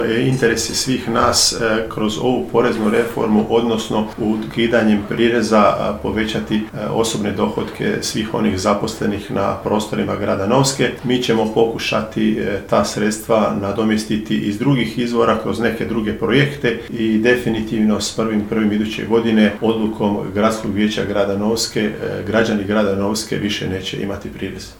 Zamjenik gradonačelnice Siniša Kesić naglašava kako je donošenje ove odluke bilo vrlo jednostavno